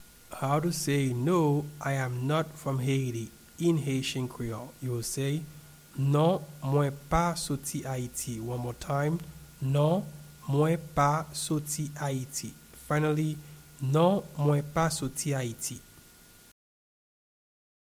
Pronunciation and Transcript:
No-I-am-not-from-Haiti-in-Haitian-Creole-Non-mwen-pa-soti-Ayiti-pronunciation.mp3